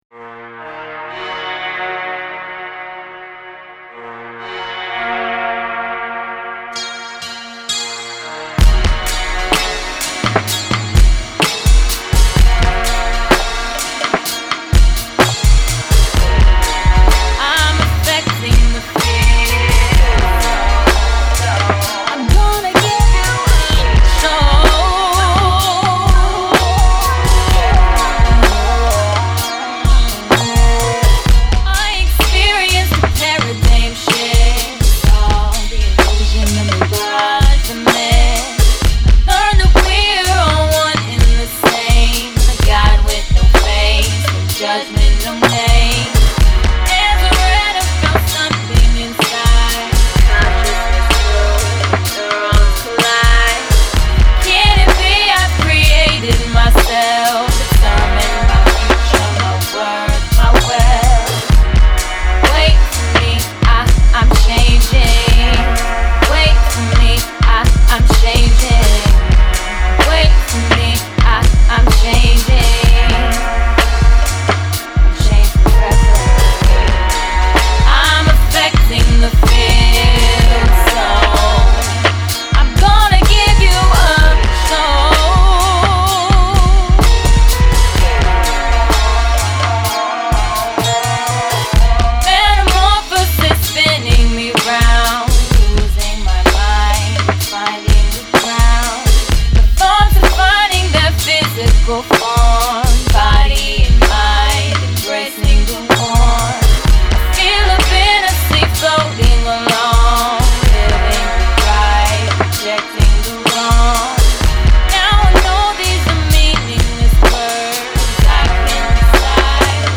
Recorded at Ground Zero Studios